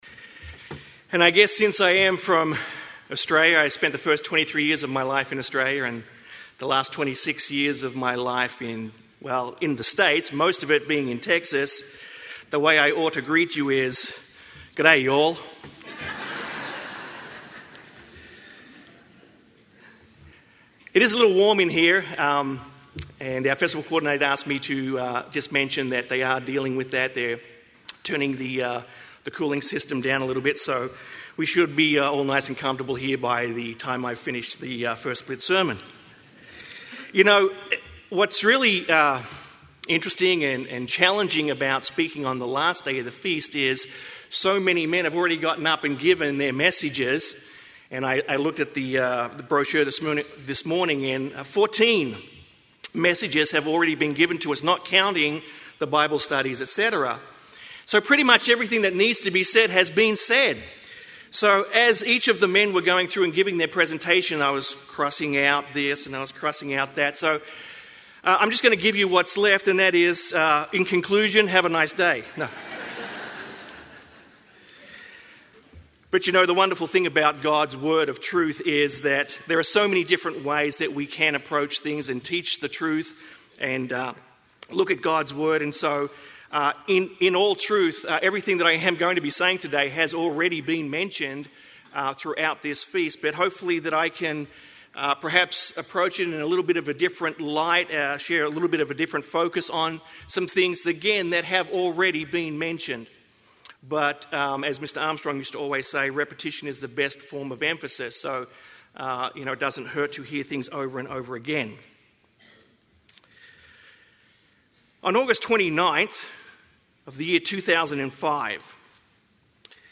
This sermon was given at the Bend, Oregon 2010 Feast site.